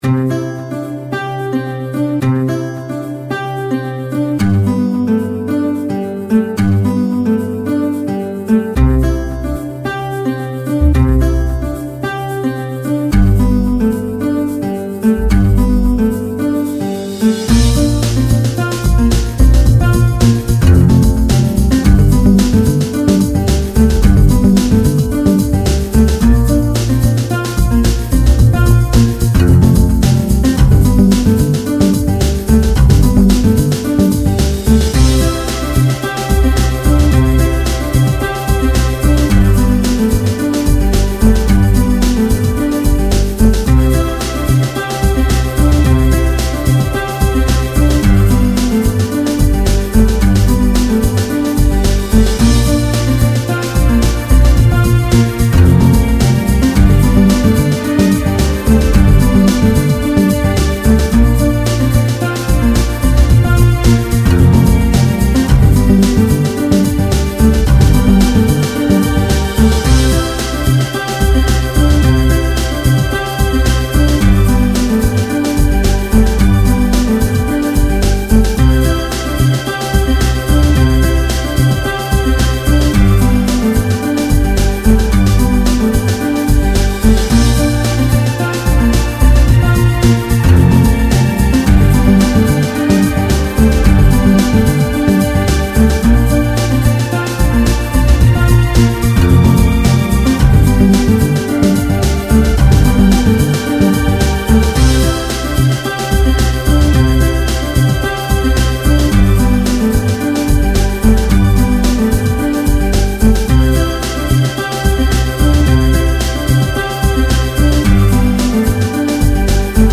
Download the musical soundtrack used in this adventure.